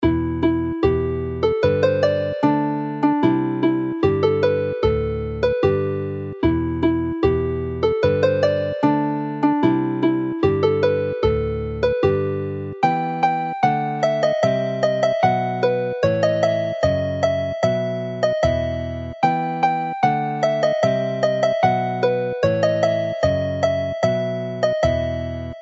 Tune to a traditional dance
Abergenni is a formal dance for four couples based on an old tune which has an Elizabethan feel.